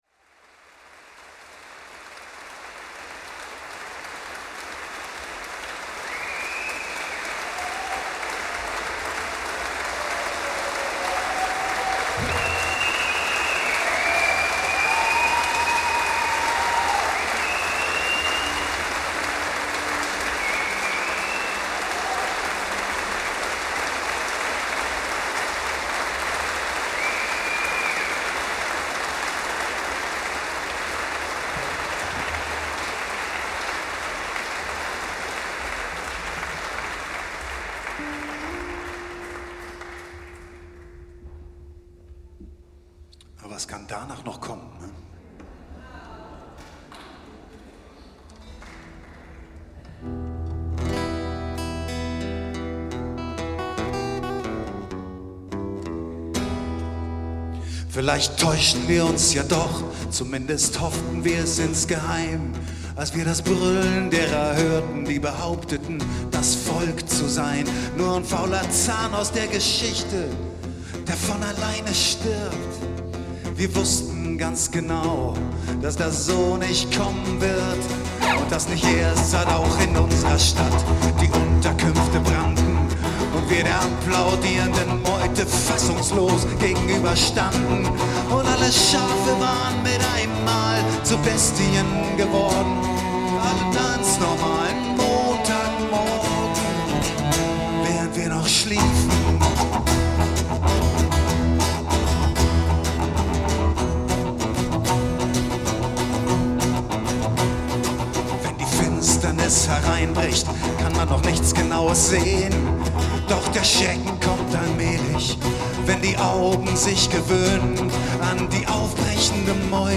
live in der Friedenskirche Grünau, 9.11.2018